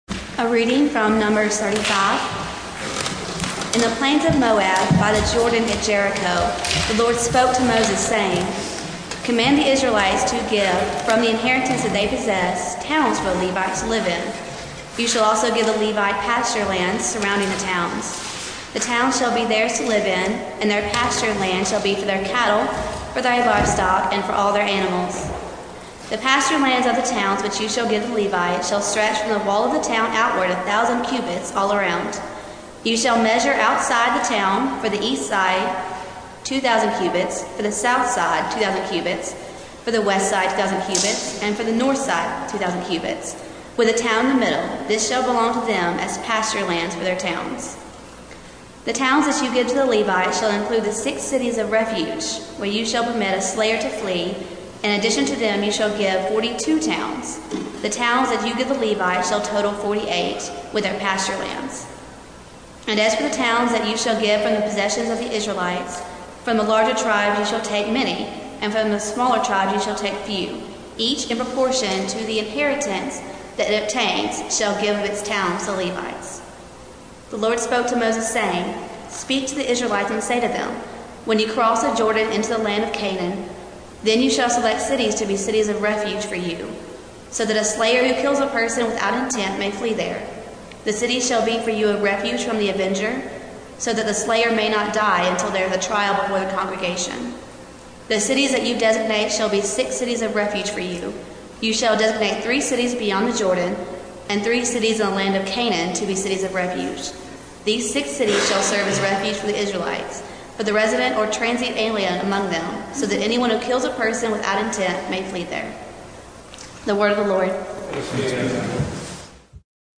Passage: Numbers 35:6-34 Service Type: Sunday Morning